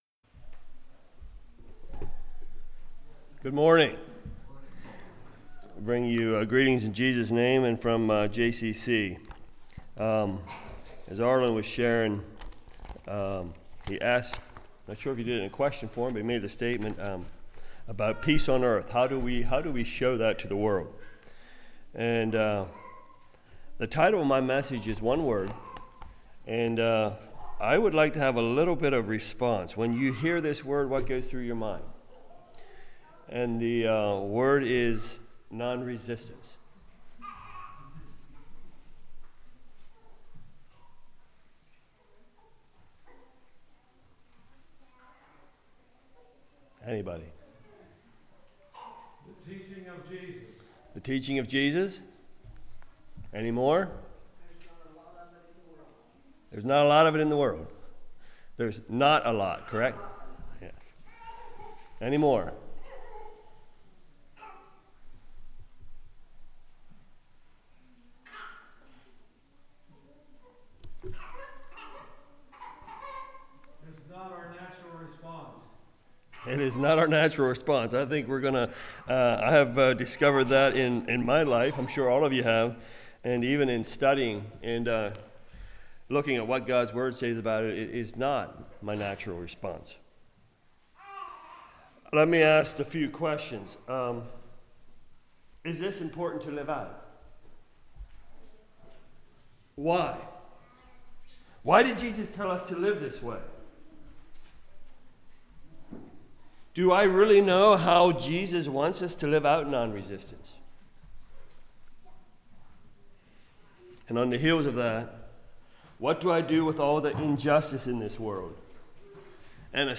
Passage: Romans 12:17-21 Service Type: Message